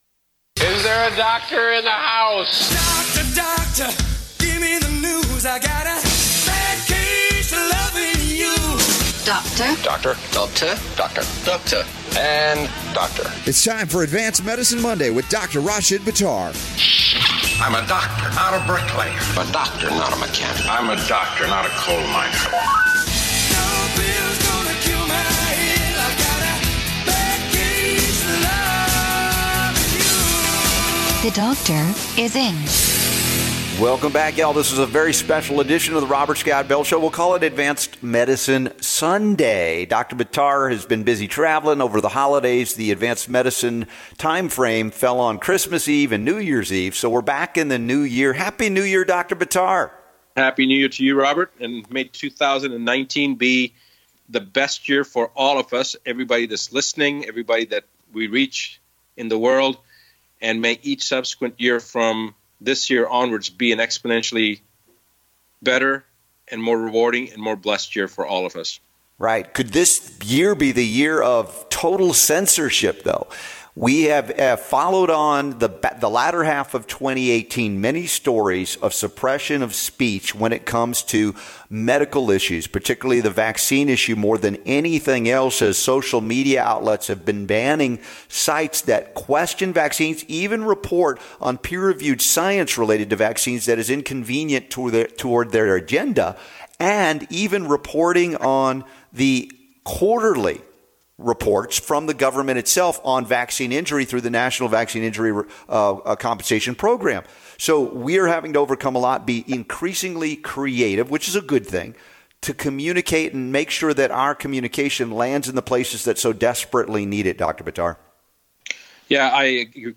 Advanced Medicine Radio Show | 1-6-2019 Get ready to learn things not traditionally taught to medical doctors!